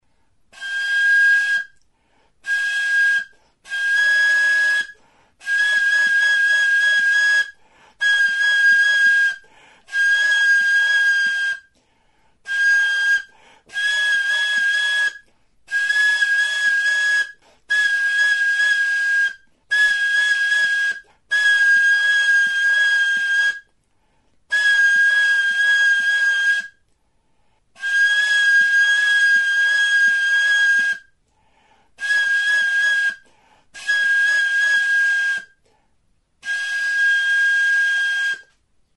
Aerophones -> Flutes -> Fipple flutes (two-handed) + kena
Recorded with this music instrument.
Banbuzko tutua da. Goiko muturrean moko flauta motako ahokoa du. Tonu aldaketarako 4 zulo ditu aurrekaldean. Beheko muturra itxia du.
CANE; BAMBOO